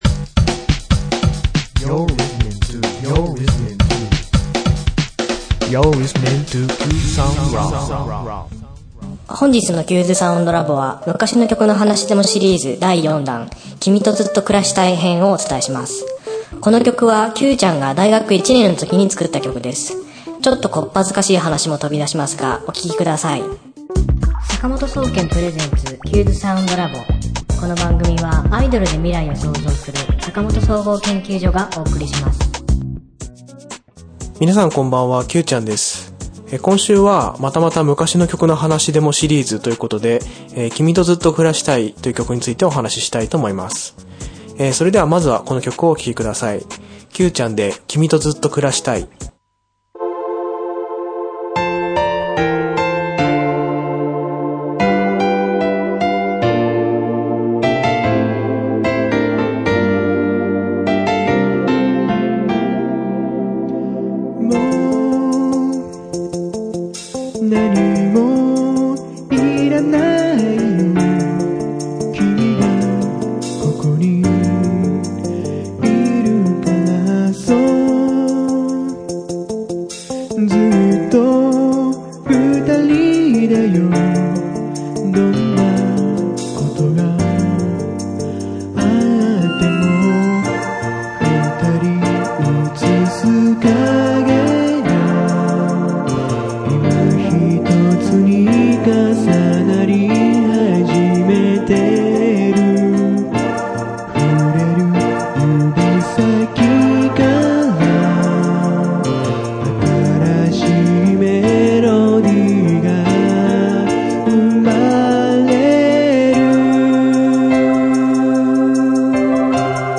かわりにエレキギターを使ったジングルを作ってみました。